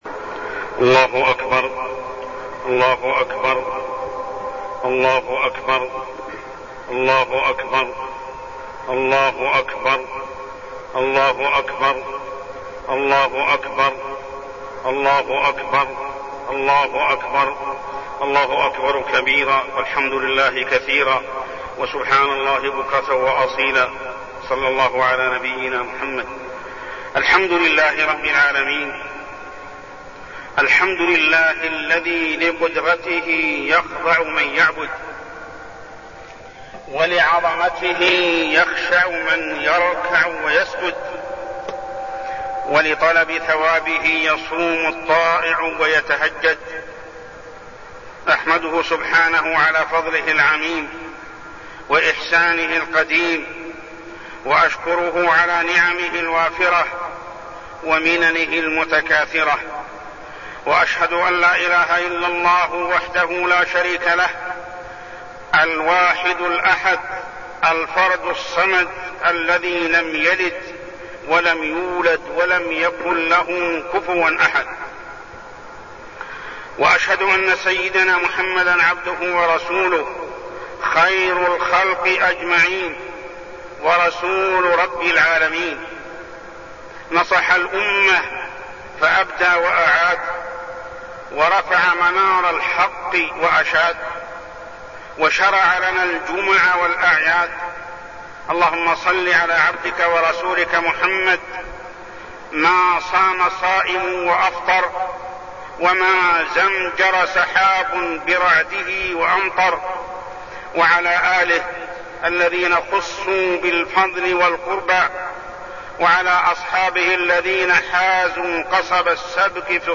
خطبة عيد الفطر -نصائح عامة
تاريخ النشر ١ شوال ١٤١٤ هـ المكان: المسجد الحرام الشيخ: محمد بن عبد الله السبيل محمد بن عبد الله السبيل خطبة عيد الفطر -نصائح عامة